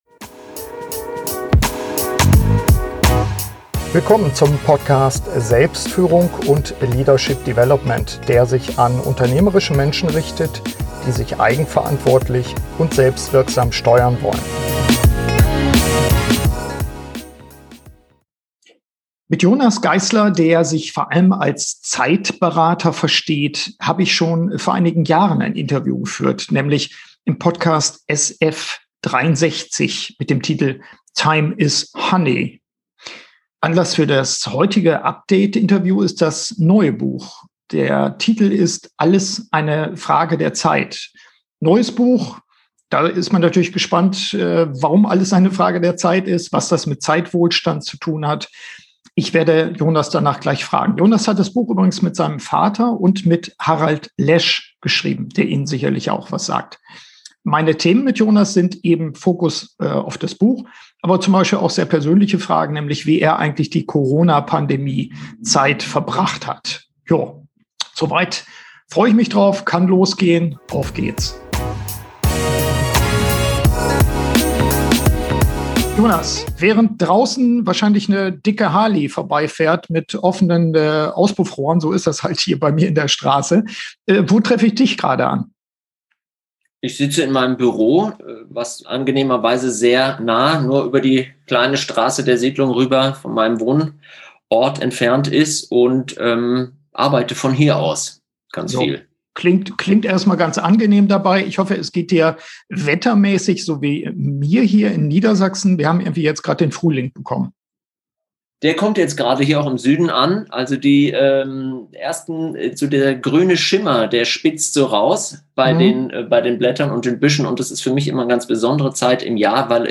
SF151 Wie erreichen wir Zeitwohlstand? - Update-Interview